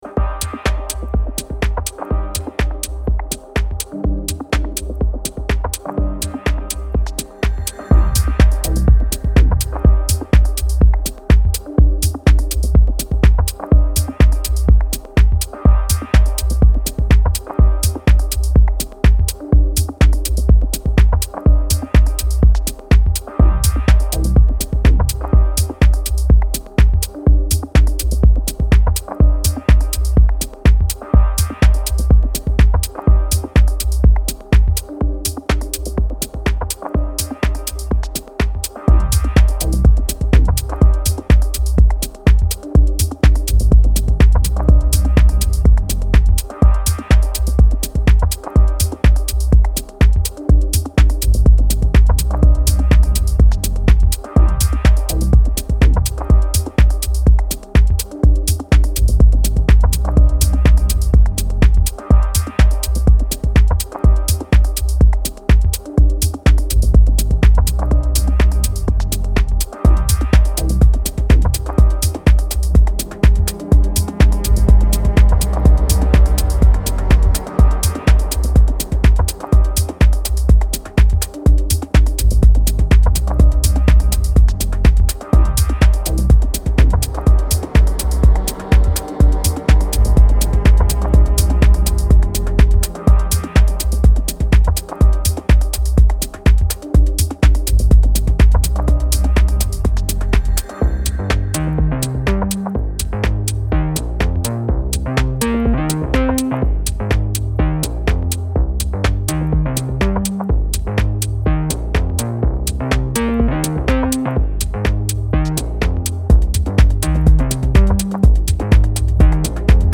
ここでは、繊細な音響処理が施されたダークでクールなミニマル・ハウスを全4曲展開。